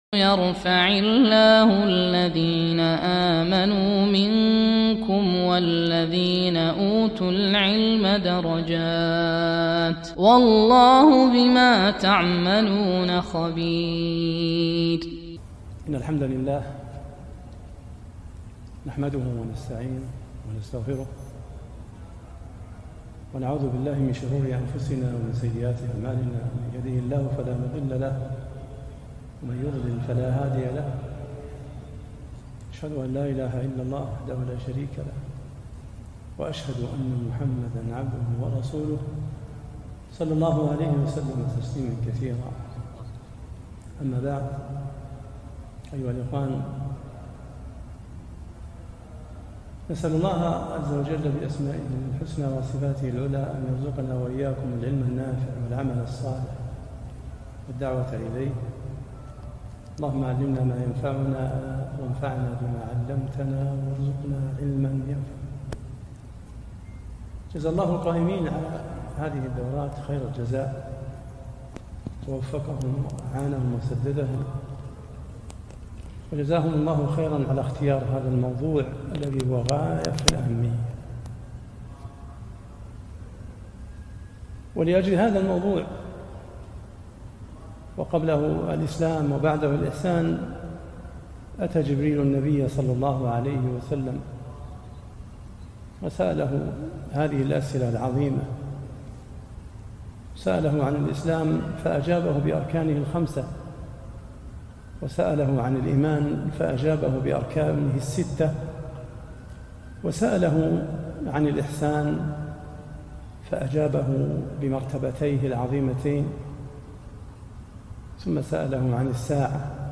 محاضرة بعنوان (الإيمان باليوم الآخر)